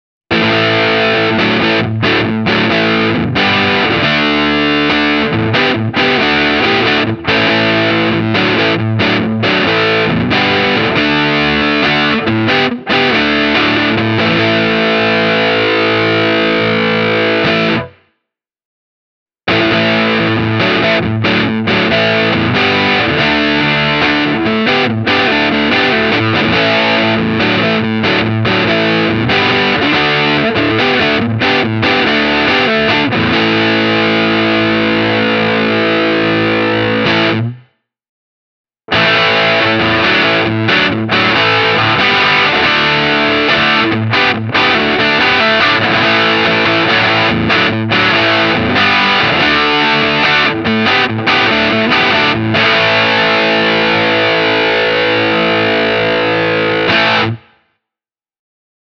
Tokai’s fine PAF-Vintage Mk3 -humbuckers work exceedingly well in this context, because these vintage-type, moderately-powered pickups have an open and dynamic sound, and don’t clog up the guitar’s mid-range frequencies.
Here’s the Tokai through an overdriven channel: